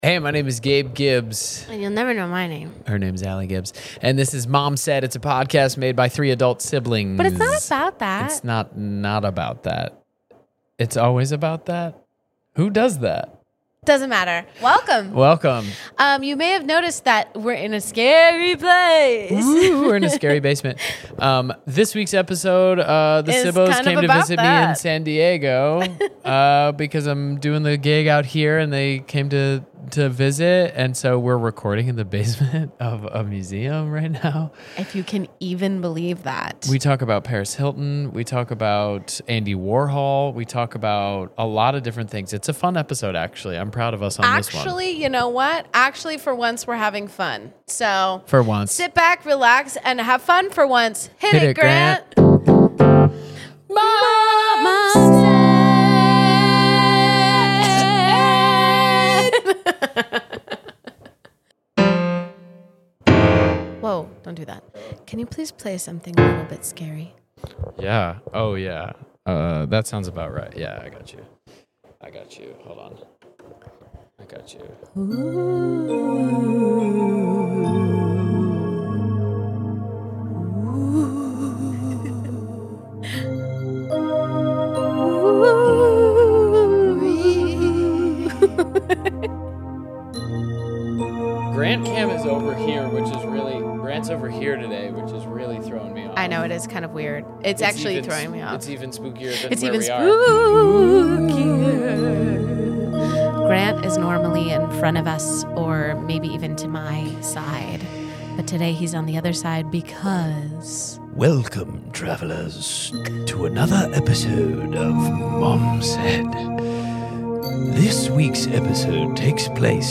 This week the siblings are coming to you from a most definitely haunted basement.